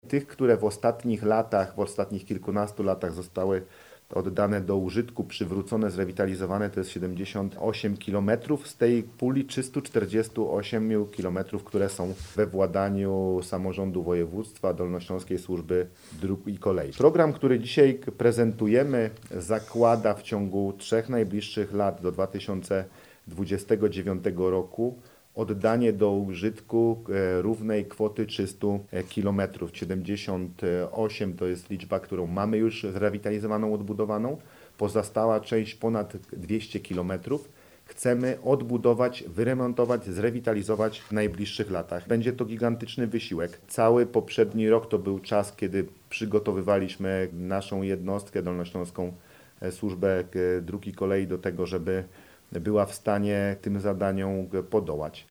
Jak podkreśla marszałek, województwo zarządza obecnie 348 kilometrami linii kolejowych i jest – po PKP PLK – drugim największym zarządcą infrastruktury kolejowej w Polsce.